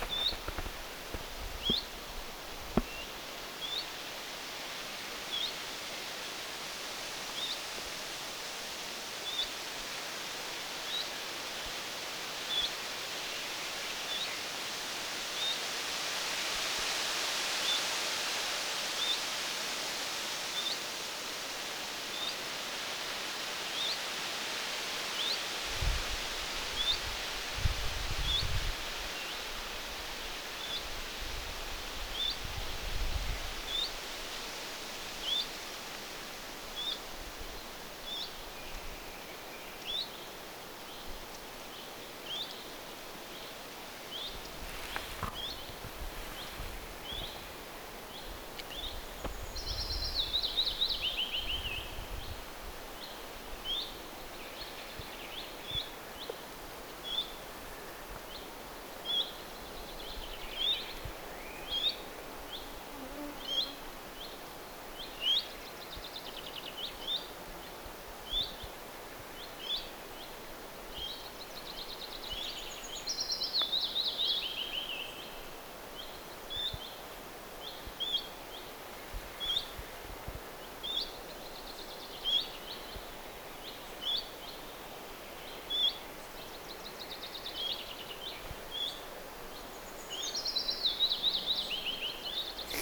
ilmeisesti pajulinnun huomioääniä
Erilaisia vaikka minkälaisia.
ilmeisesti_pajulinnun_huomioaania.mp3